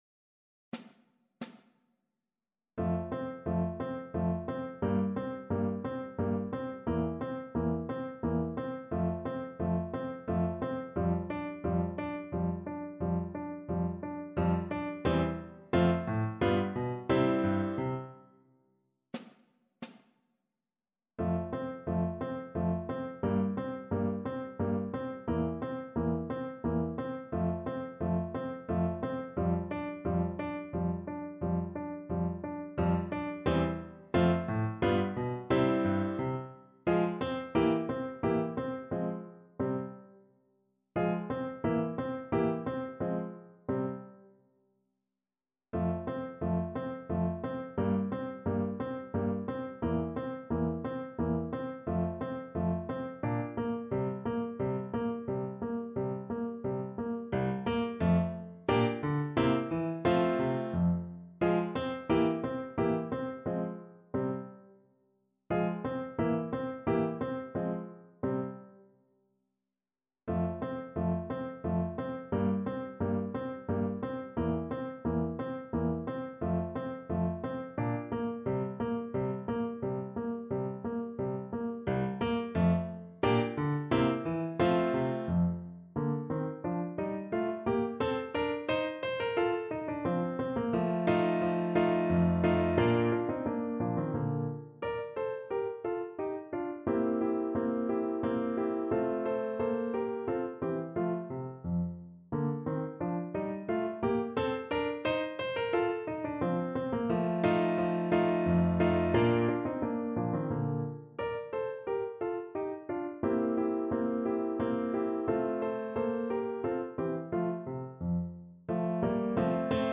Boccherini: Menuet (na klarnet i fortepian)
Symulacja akompaniamentu